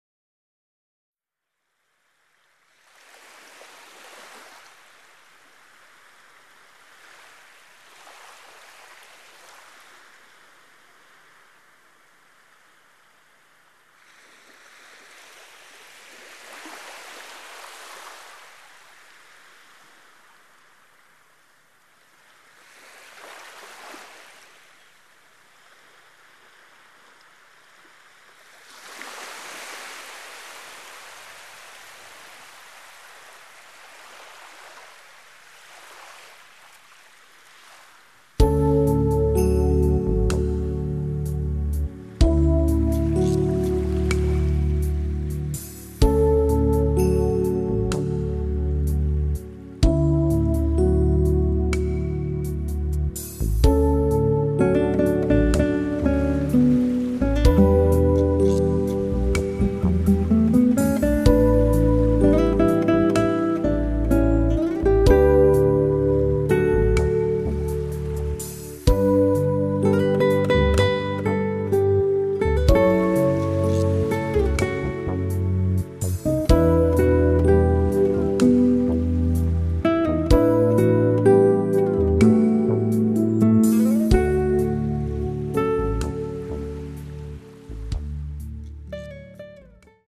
因為夏日的種種風情，在在讓人的心境融入自然之中，讓我們得以放鬆.....請享受這夏的故事吧。